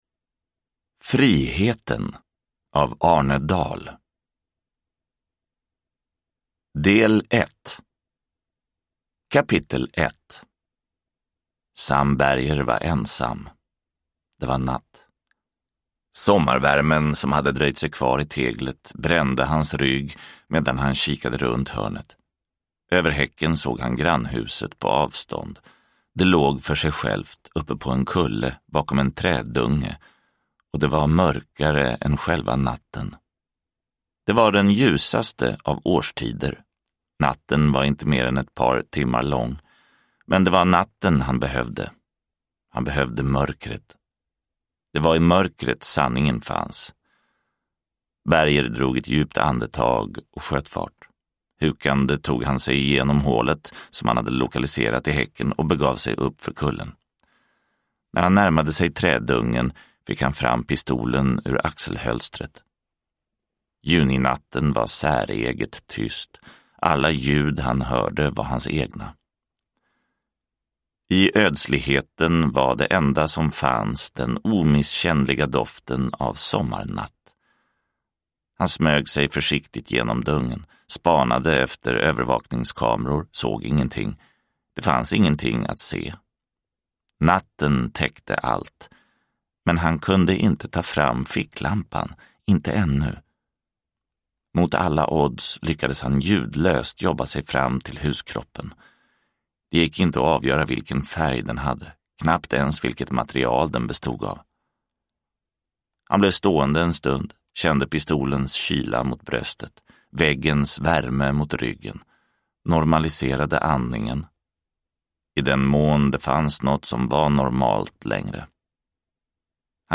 Friheten – Ljudbok – Laddas ner